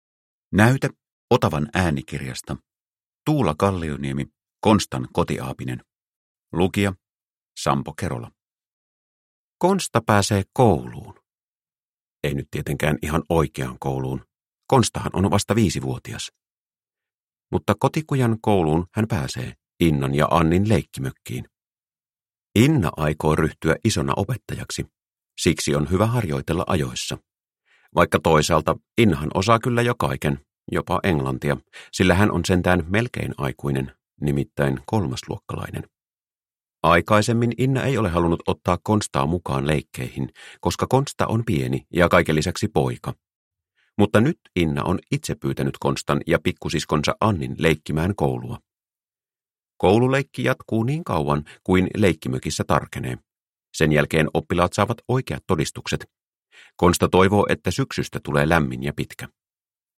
Konstan kotiaapinen – Ljudbok – Laddas ner